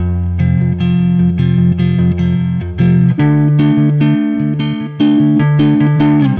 Weathered Guitar 06.wav